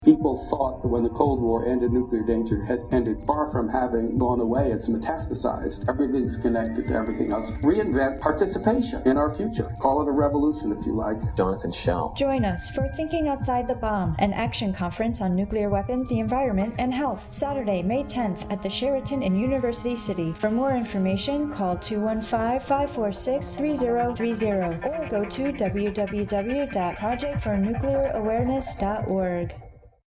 Through voiceovers, originally recorded sound and sampled music, the desired effect is acheived.
30sec_PNA_PSA_heartbeat.mp3